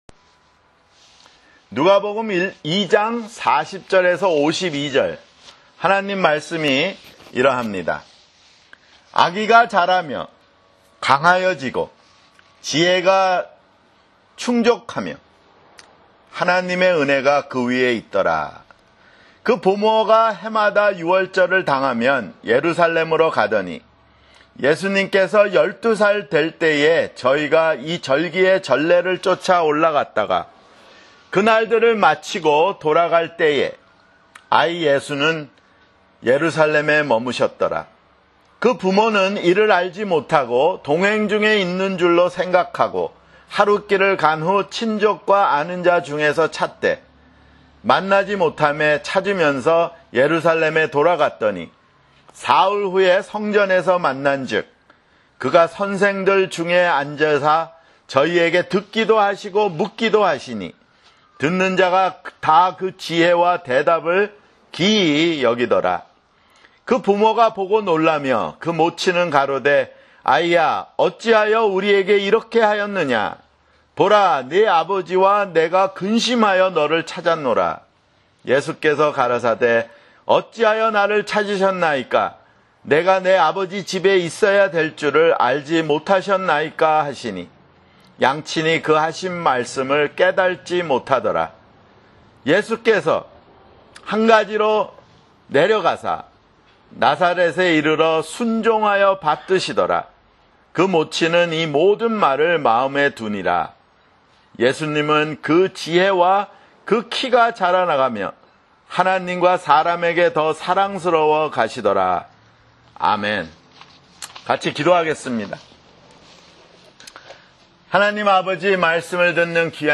[주일설교] 누가복음 (20)